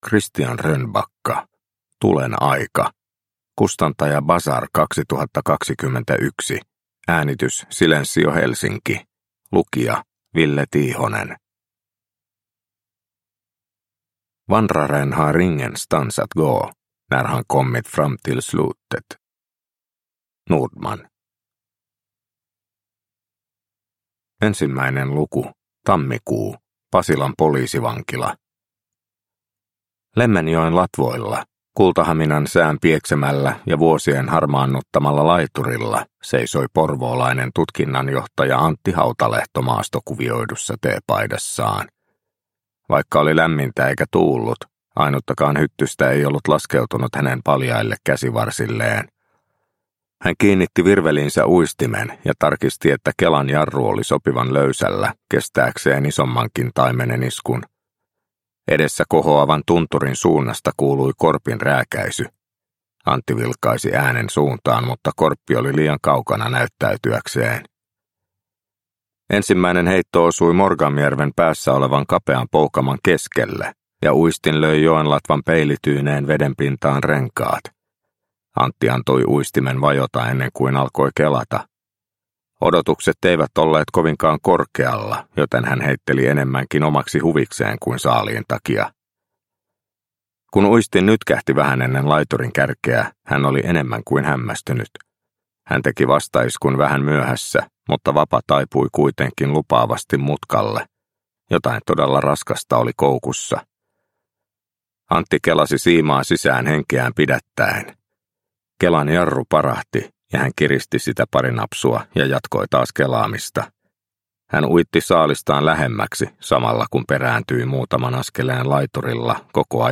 Tulen aika – Ljudbok – Laddas ner